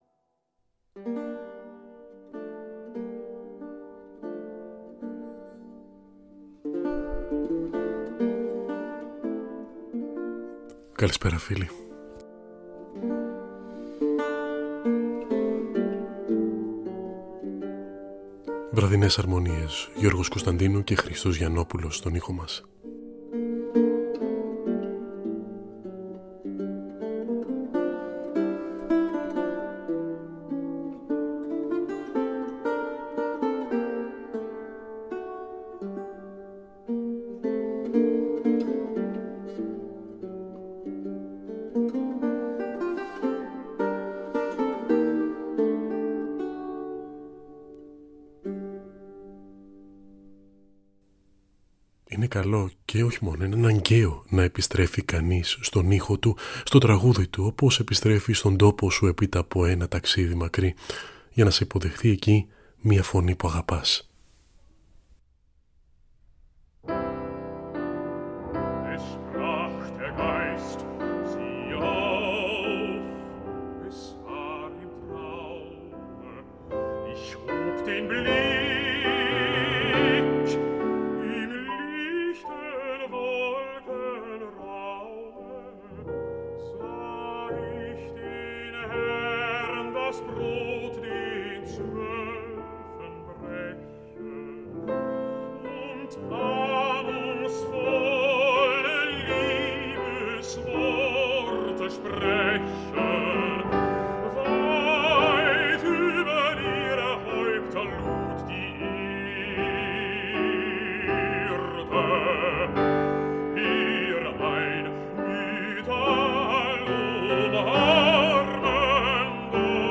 Λόγια Τραγούδια από την Αναγέννηση έως τις Μέρες μας